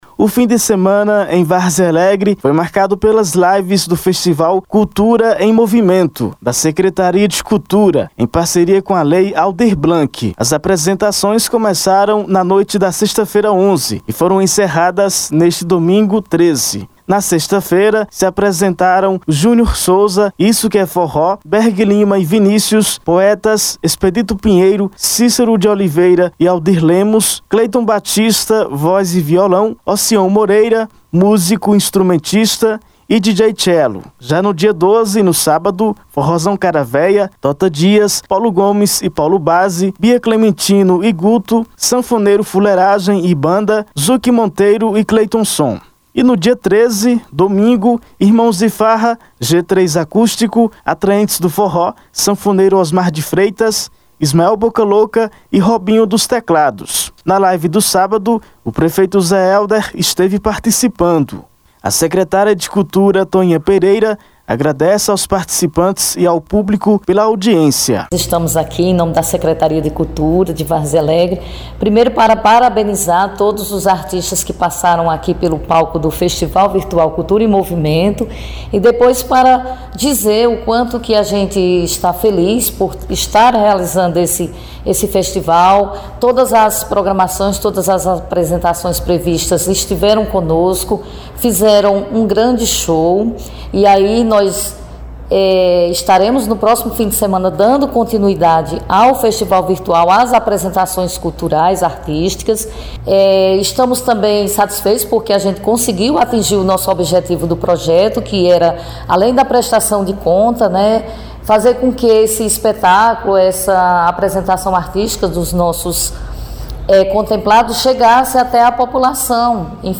Confira o áudio da reportagem: Foto Governo Municipal